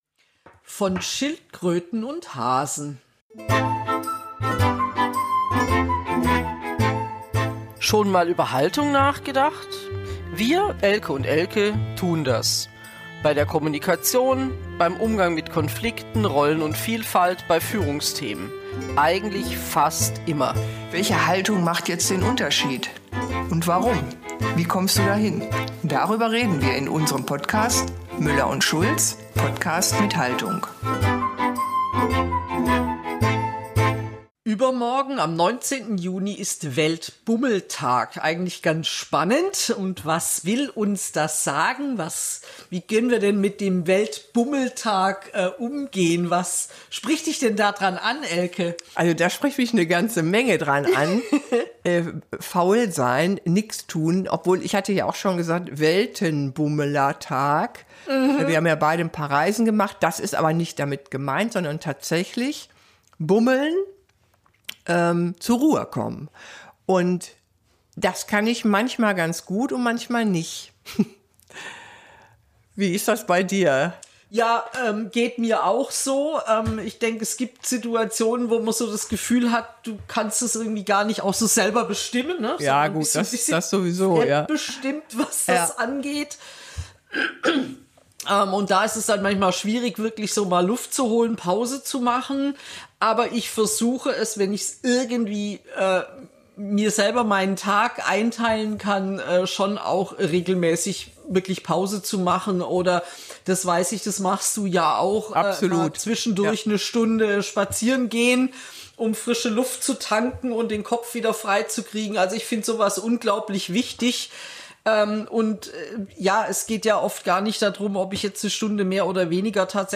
Ein tiefgehendes Gespräch über Haltung, Selbstbestimmung und den Mut, einfach mal nichts zu tun.